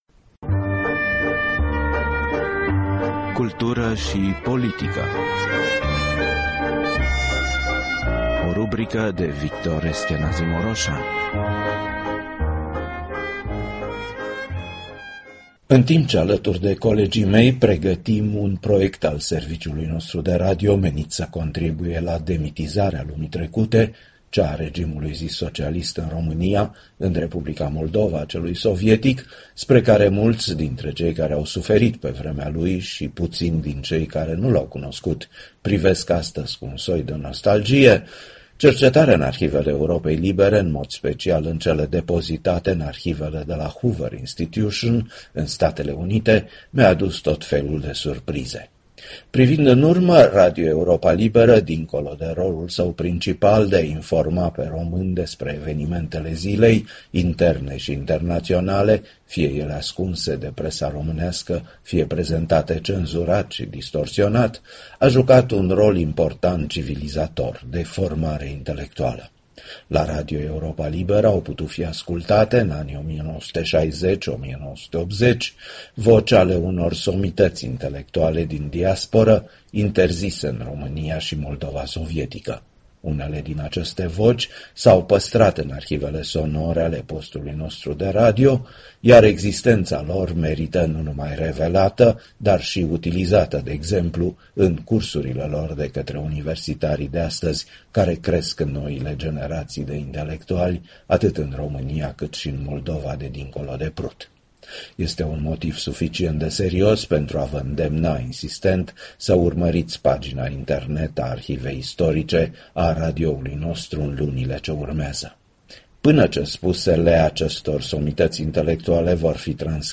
Contribuțiile sale la Radio Europa Liberă au evidențiat fapte din această lucrare, între care și starea de spirit a populației românești și atitudinea față de unire, comentată de un istoric rus într-o publicație berlineză, în 1921. Ascultați așadar vocea lui Anton Crihan, extrase dintr-o emisiune, vă reamintesc, de la 27 martie 1970:
Ați ascultat vocea economistului Anton Crihan, de la St. Louis din Statele Unite, un fost membru al Sfatului Țării în 1918.